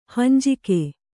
♪ hanjike